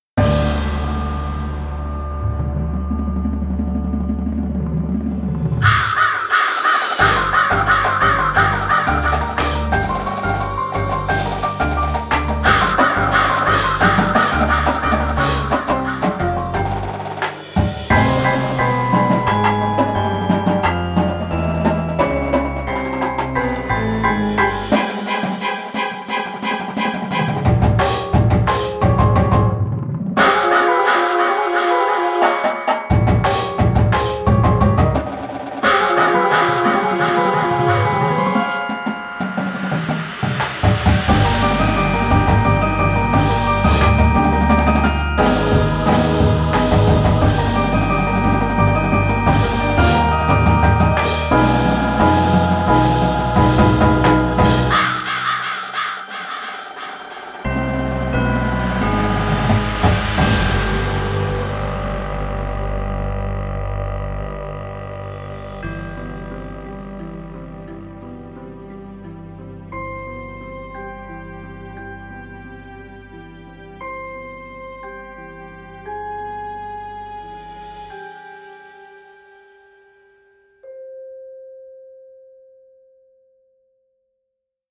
Full Ensemble Percussion